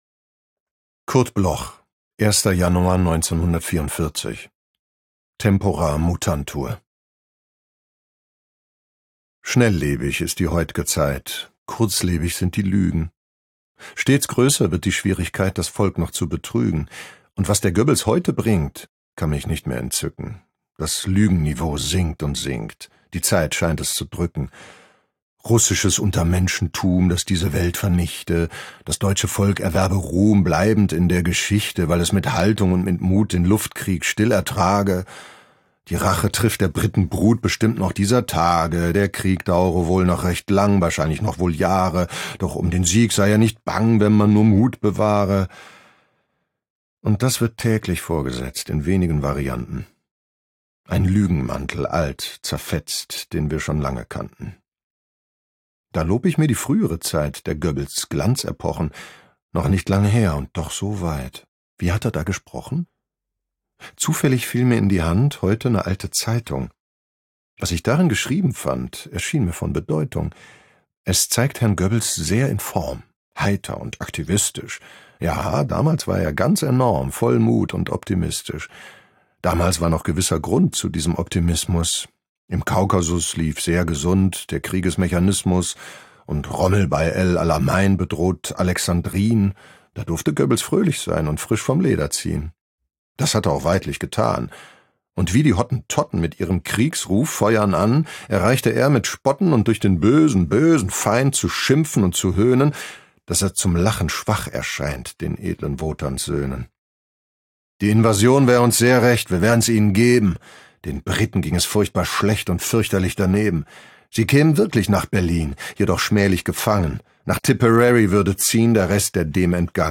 Aufnahme: speak low, Berlin · Bearbeitung: Kristen & Schmidt, Wiesbaden
Jörg Hartmann (* 1969) ist ein deutscher Schauspieler, Hörbuchsprecher und Drehbuchautor.